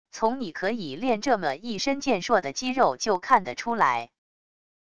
从你可以练这么一身健硕的肌肉就看得出来wav音频生成系统WAV Audio Player